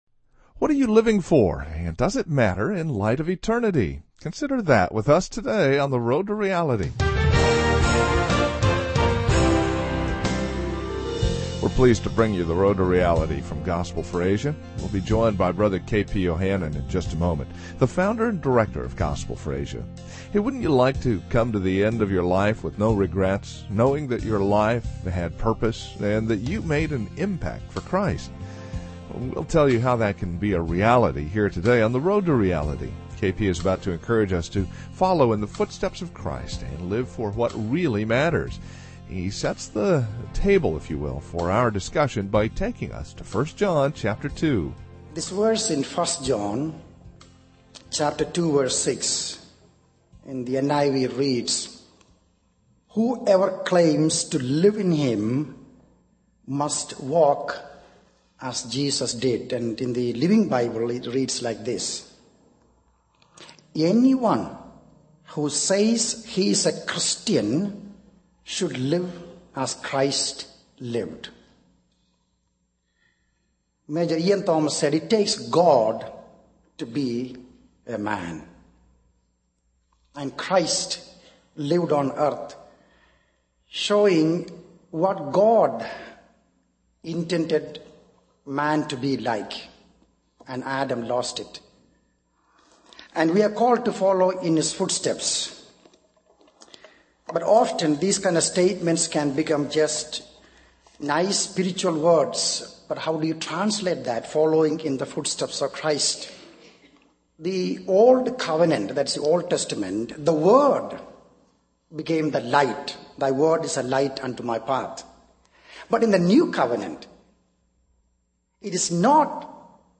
This sermon emphasizes the impact of sharing the gospel and serving in mission fields, highlighting stories of conversions, sacrifices, and the need for compassion and prayer. It calls for a commitment to intercessory prayer, a shift in focus from materialism to supporting missions, and a willingness to send and support missionaries to reach the lost world.